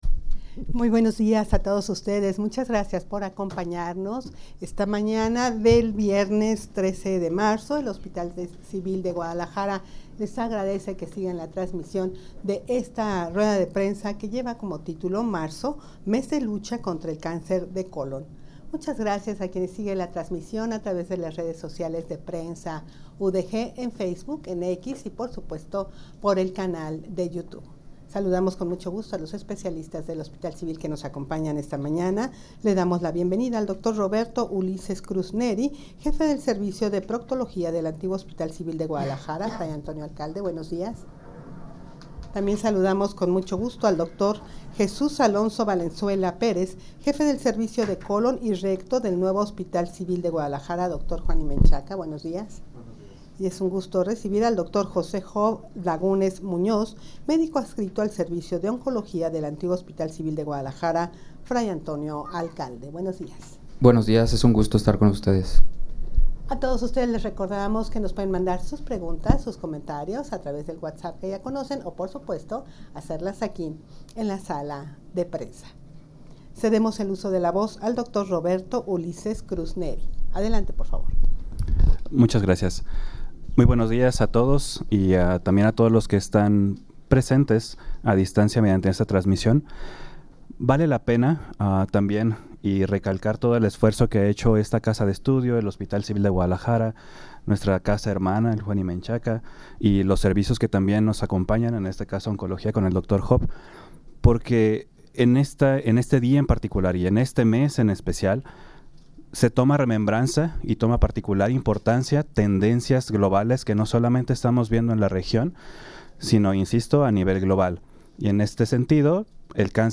Audio de la Rueda de Prensa
rueda-de-prensa-marzo-mes-de-lucha-contra-del-cancer-de-colon.mp3